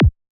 Here You can listen to some of the Kicks included in the sample library:
• Versatile and Dynamic: Whether you're producing trance, psytrance, or any other high-energy electronic music, these kicks will add that essential punch to your tracks.
KICK-X-196.wav